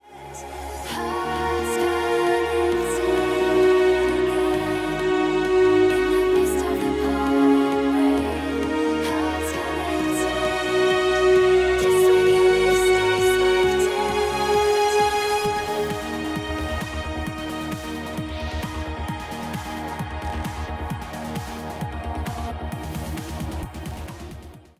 Где найти Strings, которые начинаются с 2:06?